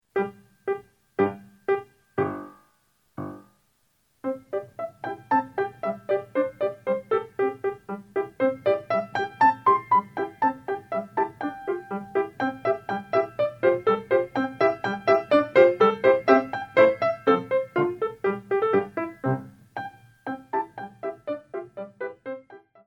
These are original pieces in a classical style.